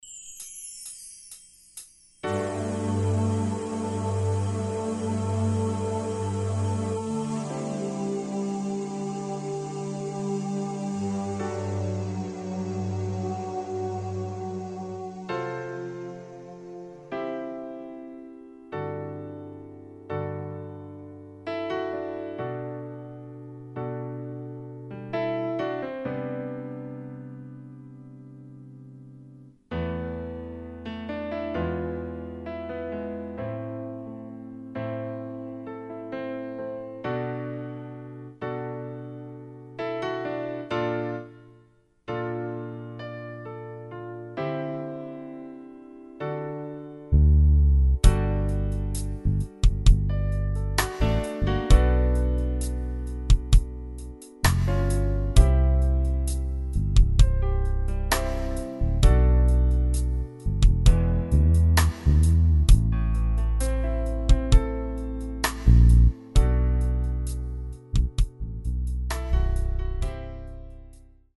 Key of D
Backing track only.